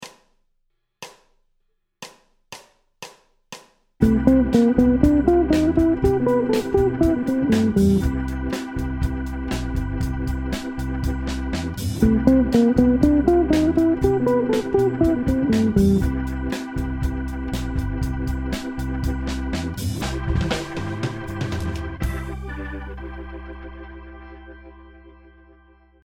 C La phrase démarre par un motif Bebop de montée de gamme qui vise successivement la Septième Majeure puis la Tierce